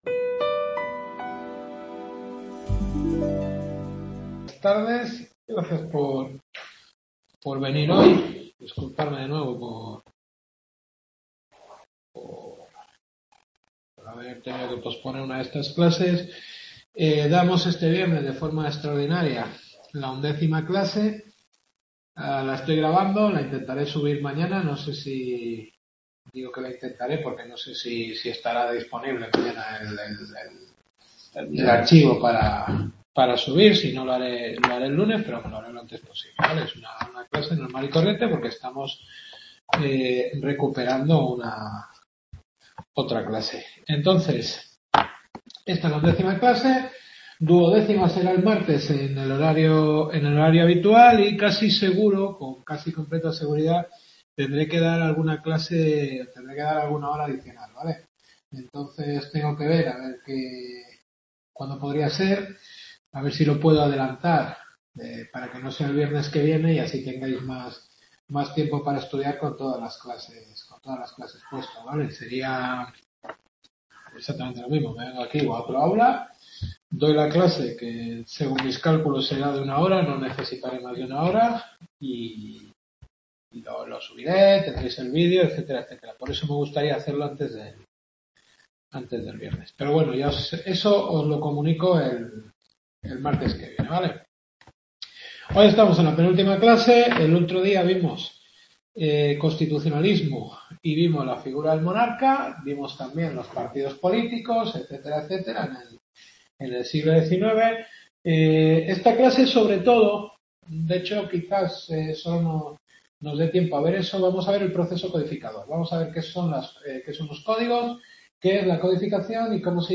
Cultura Europea en España. Undécima Clase.